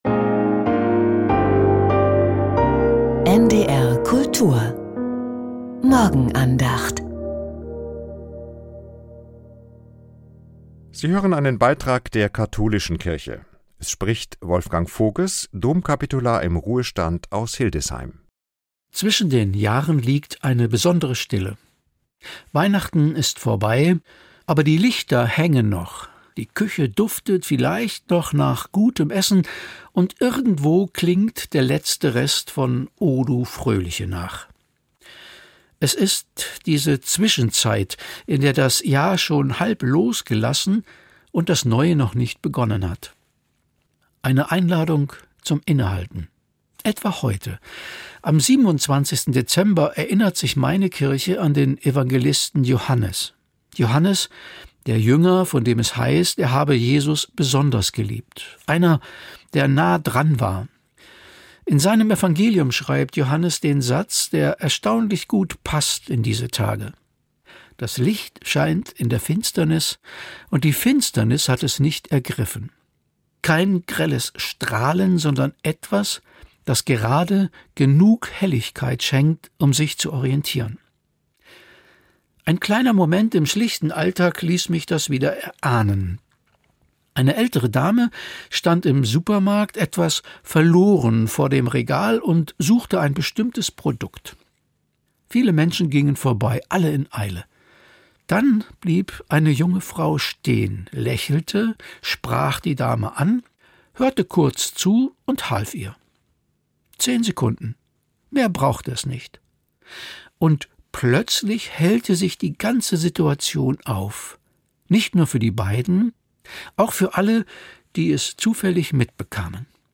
Was schon da ist ~ Die Morgenandacht bei NDR Kultur Podcast